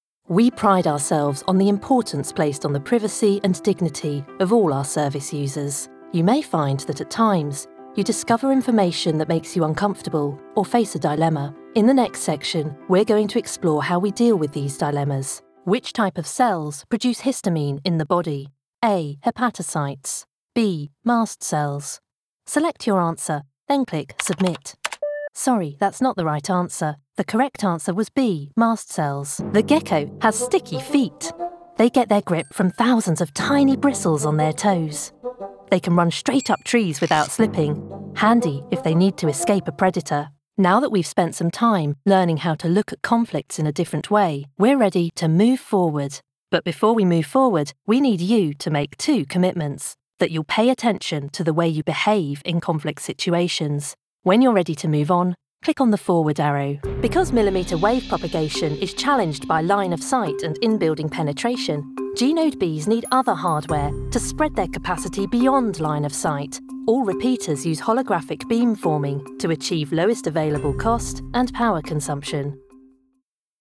E-learning
Meu sotaque é britânico neutro, com tons RP e londrinos que podem ser naturalmente formais ou informais, dependendo do estilo necessário.
Tenho um estúdio de gravação doméstico construído para esse fim, o que significa que posso produzir áudio com qualidade de transmissão rapidamente e a um preço competitivo.
Microfone: Rode NT1
Jovem adulto
Meia-idade